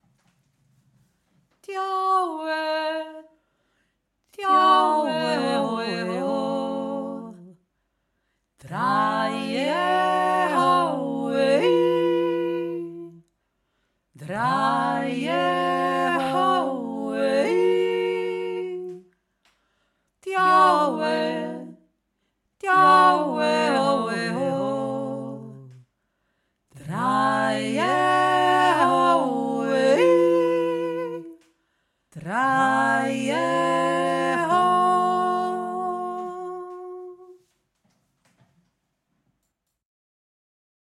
Der Kanon
dreistimmig (haben wir so nicht gemacht)
dio-e-dreistimmig.mp3